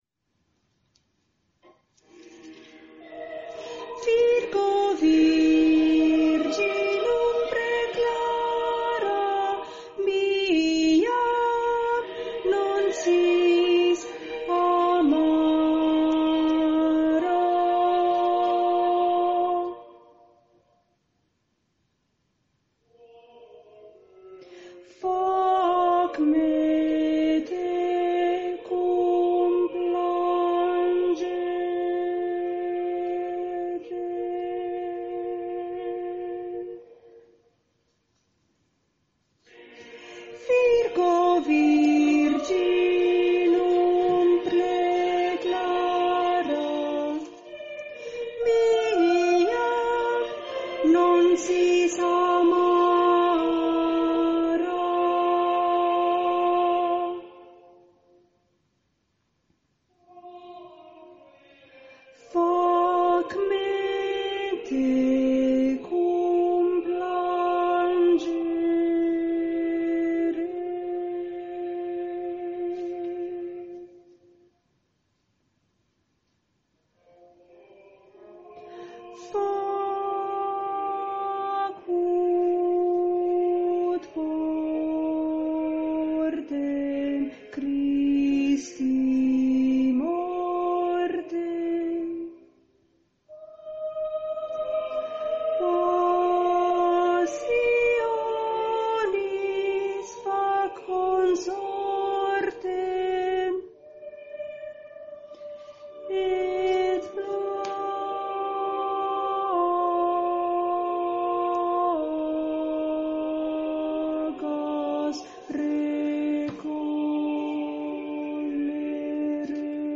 per soli, coro ed orchestra
Stabat Mater - Contralti_coro 3_parte cantata